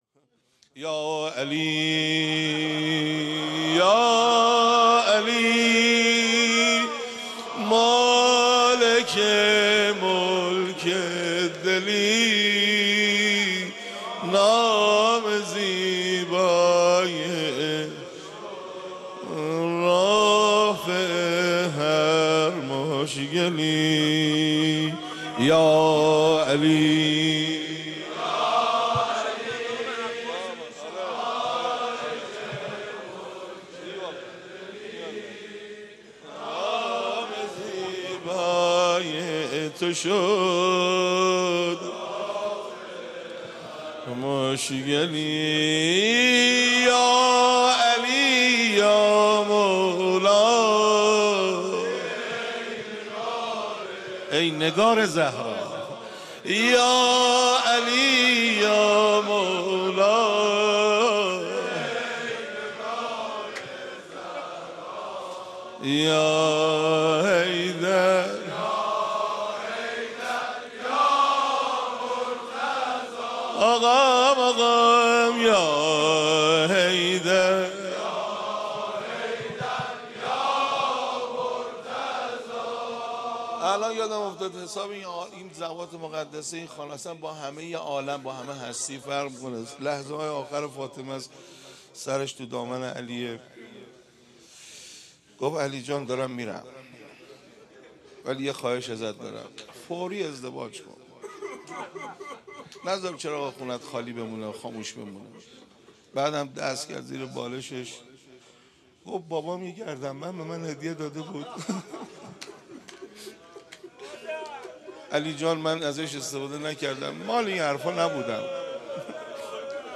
قالب : مدح